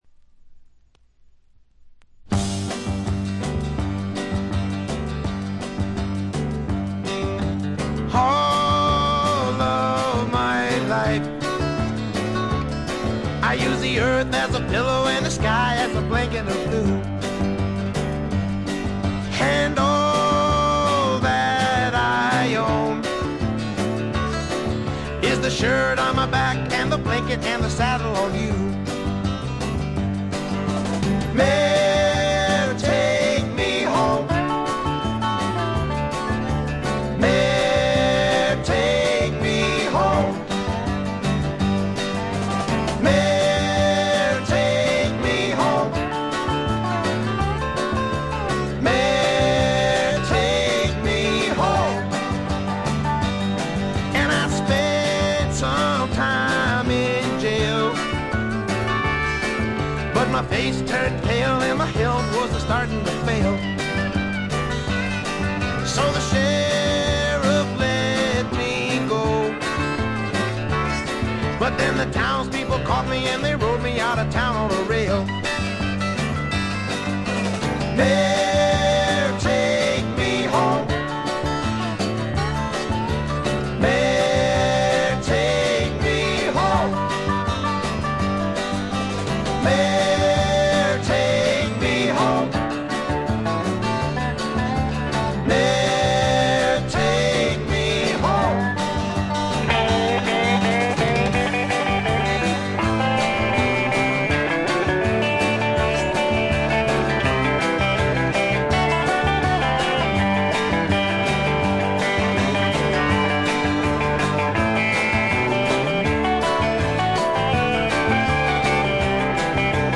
カントリー風味を加えています。カントリーロック的な曲とフォークロック的な曲の組み合わせ加減もとてもよいです。
Guitar, Guitar (Rhythm), Vocals